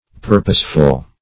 Purposeful \Pur"pose*ful\, a.